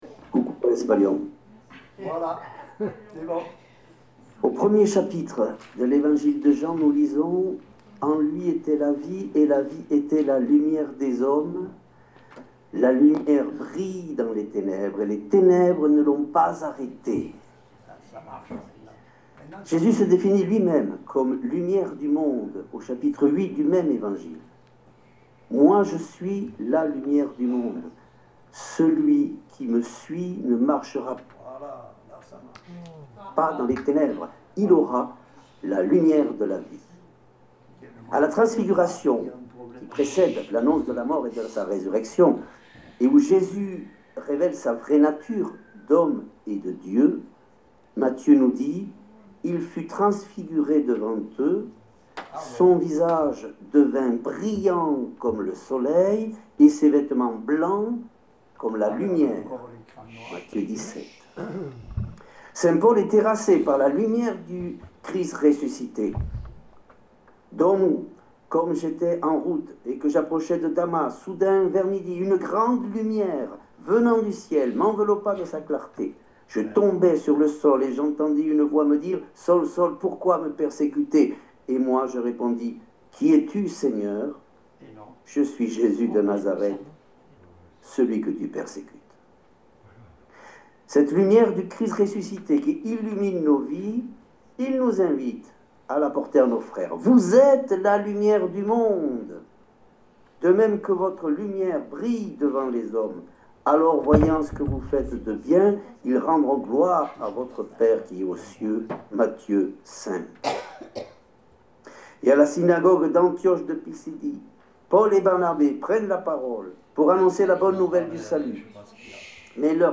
Conférence donné à la maison St Pierre, à Rodez, le 20 février 2026.